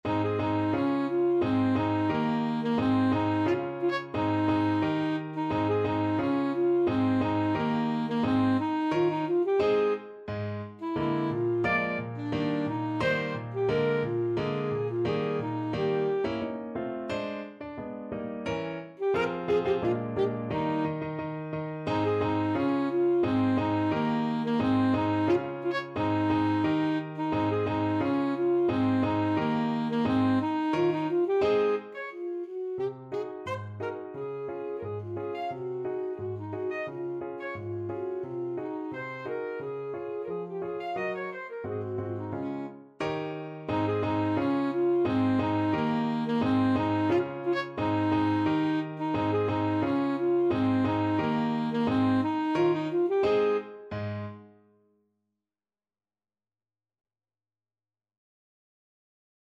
Alto Saxophone
~ = 88 Stately =c.88
2/4 (View more 2/4 Music)
Ab major (Sounding Pitch) F major (Alto Saxophone in Eb) (View more Ab major Music for Saxophone )
Classical (View more Classical Saxophone Music)